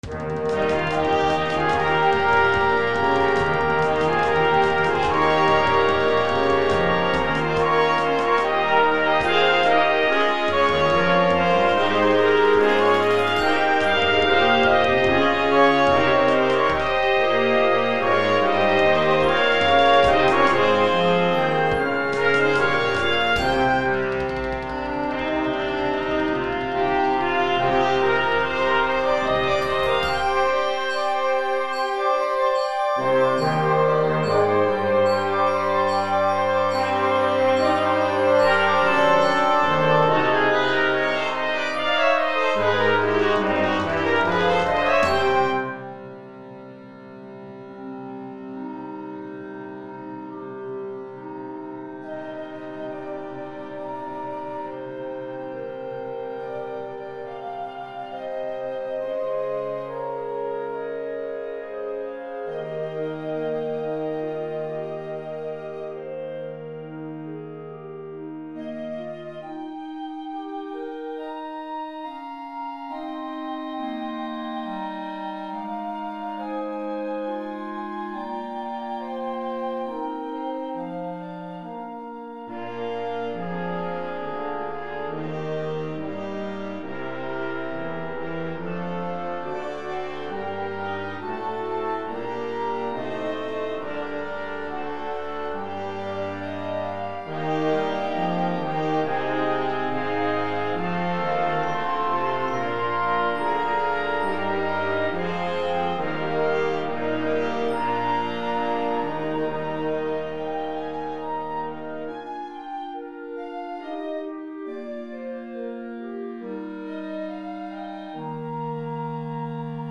Concert Band Grade 5